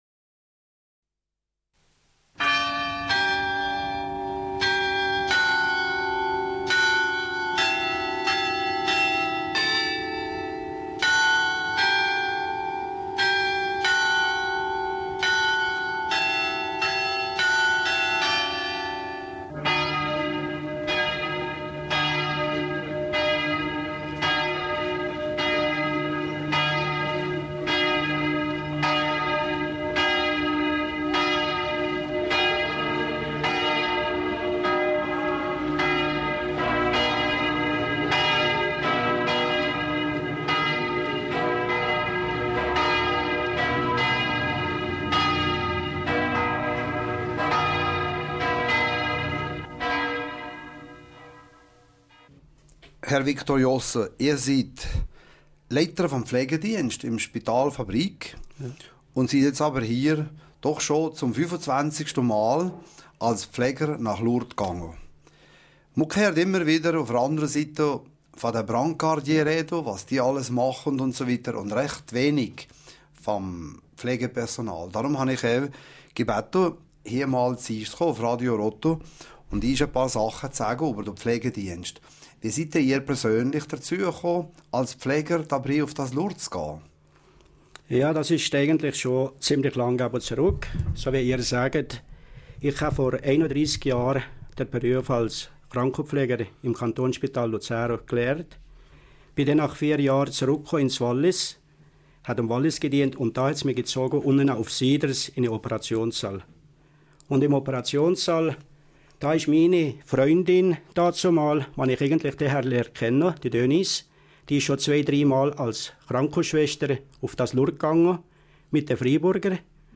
Radiosendung - émission radio